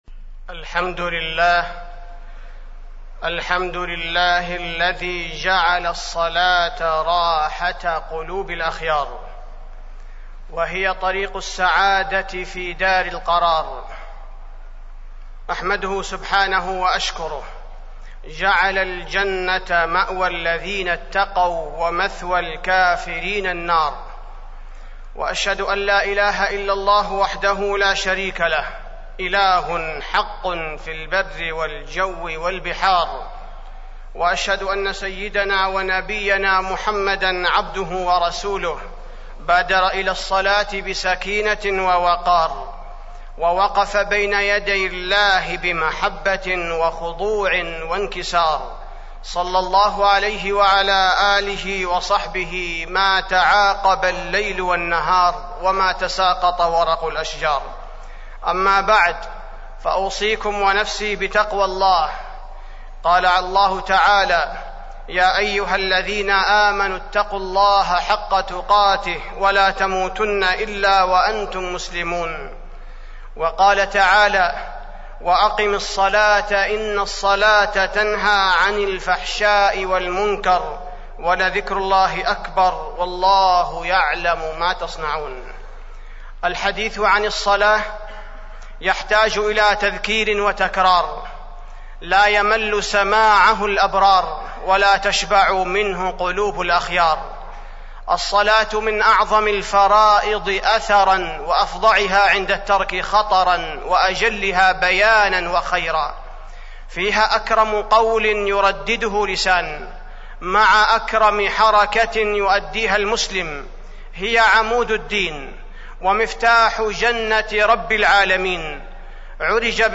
تاريخ النشر ١٦ جمادى الآخرة ١٤٢٦ هـ المكان: المسجد النبوي الشيخ: فضيلة الشيخ عبدالباري الثبيتي فضيلة الشيخ عبدالباري الثبيتي الخشوع في الصلاة